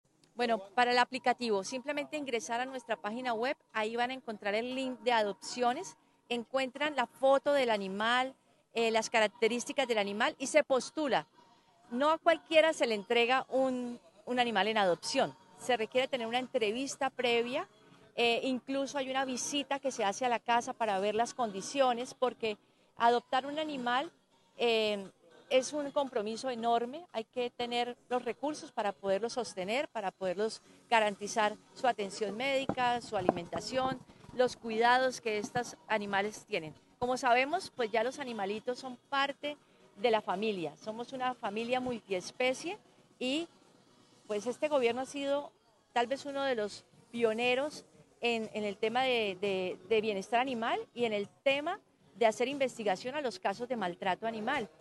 Claudia Amaya, secretaria de Salud y Ambiente de Bucaramanga